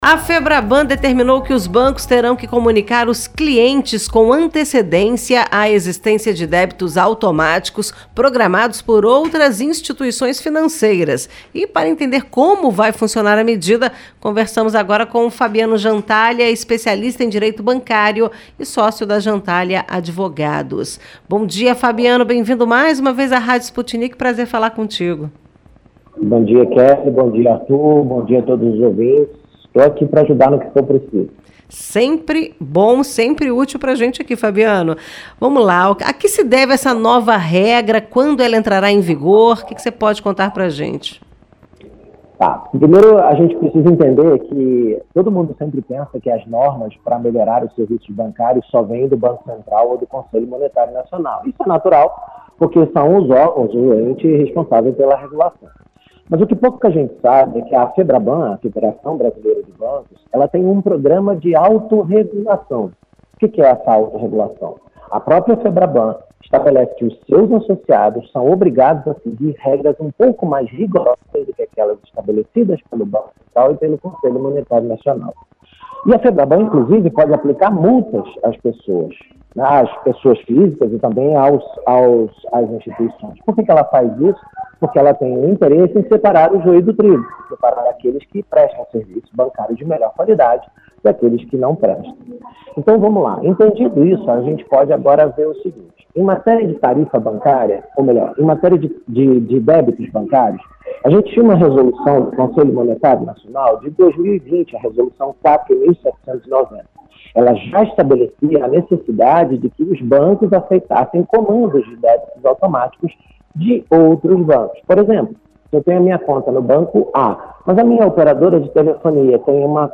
O cliente será informado com antecedência sobre o valor, data e beneficiário do débito, podendo contestar ou cancelar a operação. Em entrevista à Rádio Sputnik